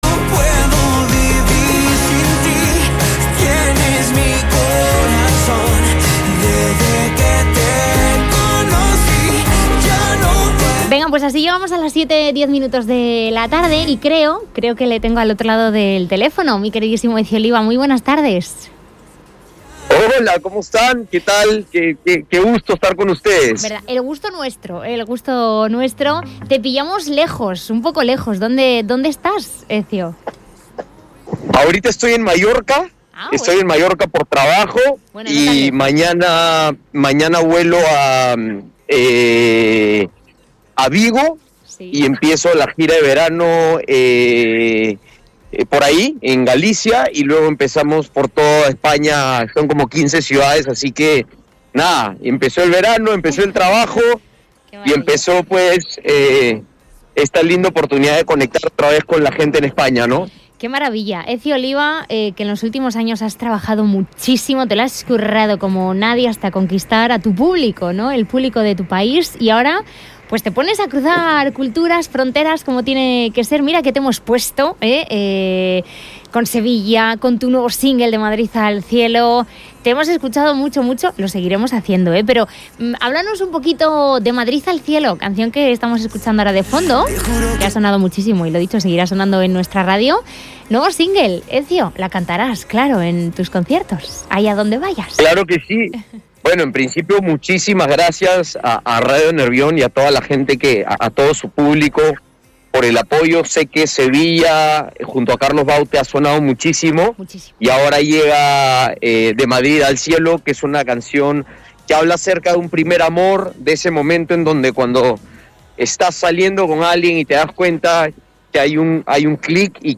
Entrevista a Ezio Oliva
ENTREVISTA-EZIO-OLIVA.mp3